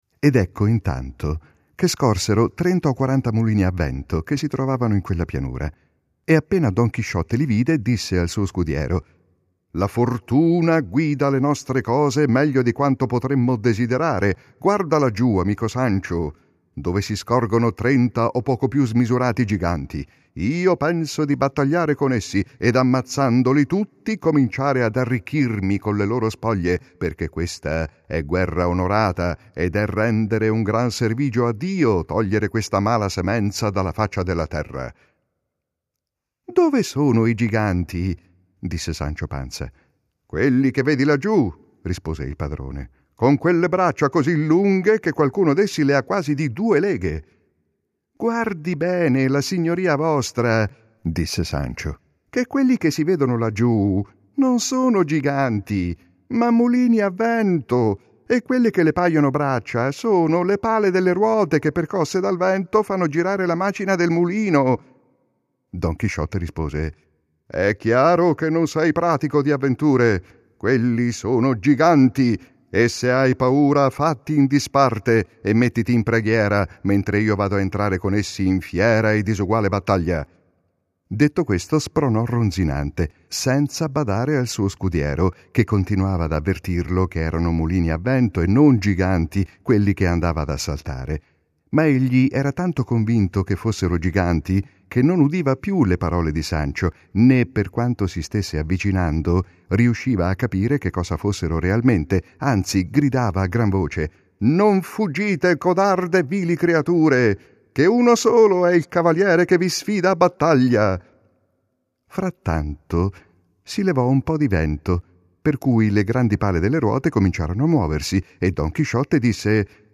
©2017 audiolibro